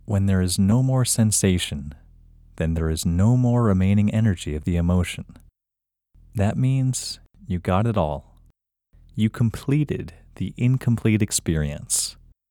IN – Second Way – English Male 26